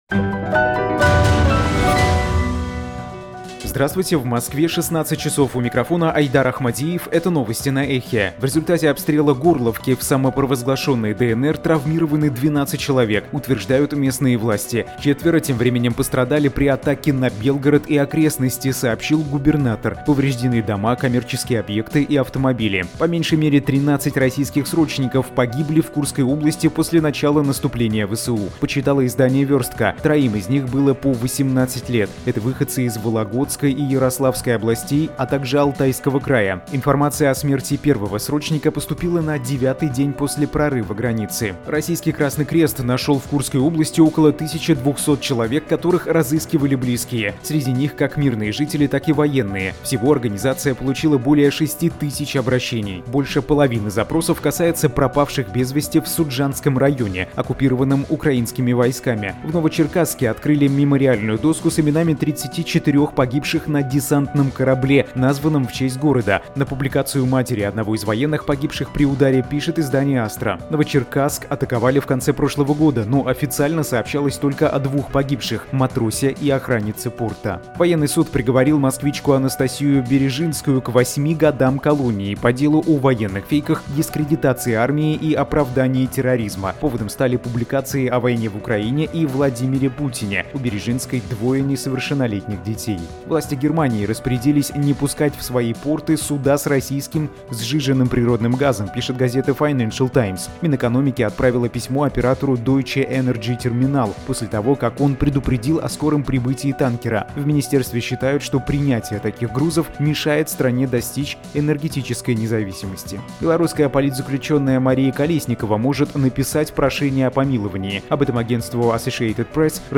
Слушайте свежий выпуск новостей «Эха».